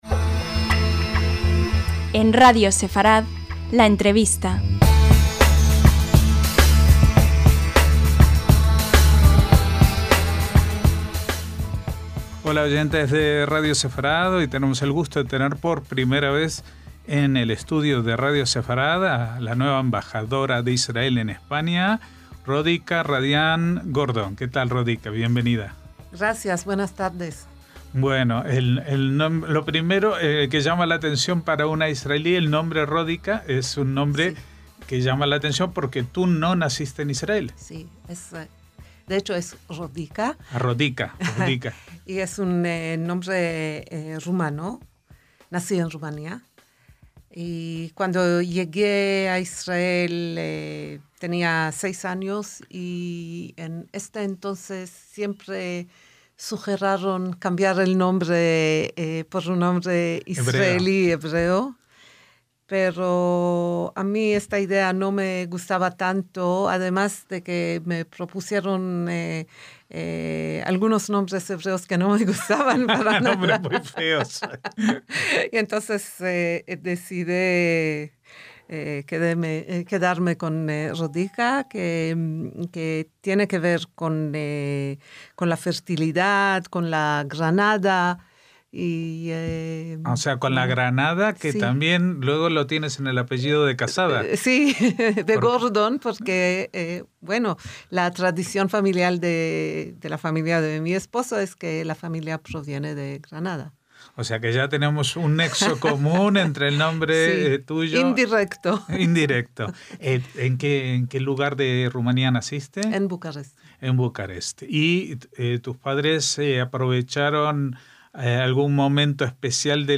LA ENTREVISTA - Quizás hayan visto en Internet su breve vídeo de presentación.